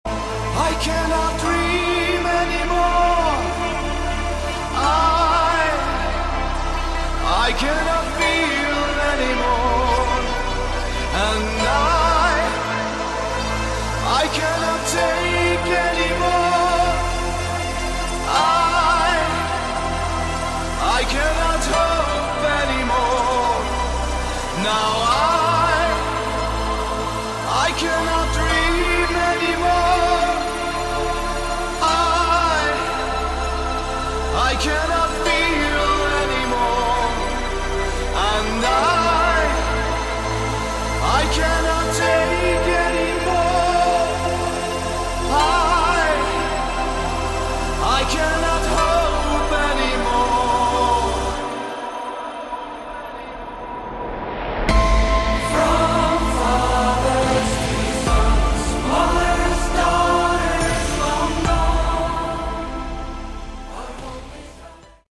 Category: Symphonic Hard Rock
synthesizers, vocals
rhythm, lead & acoustic guitars, bass, lead vocals